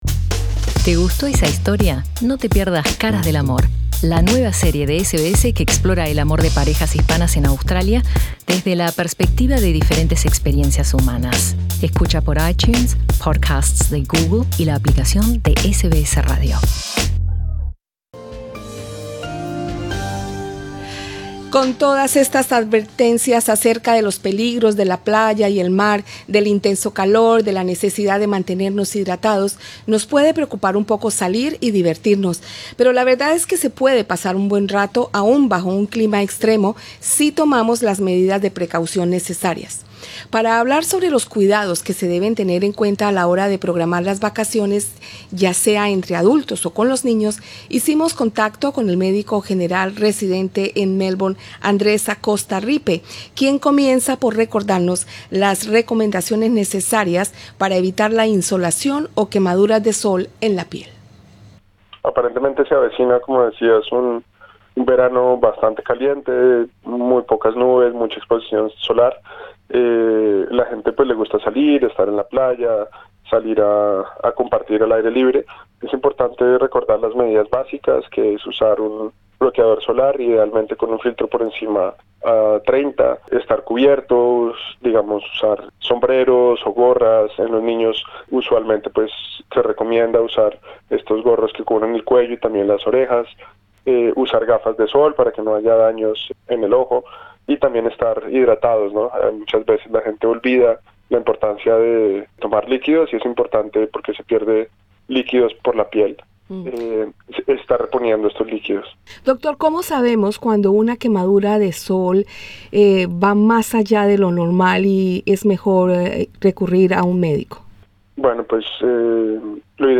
Escucha arriba en el podcast la entrevista.